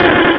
pokeemerald / sound / direct_sound_samples / cries / kecleon.aif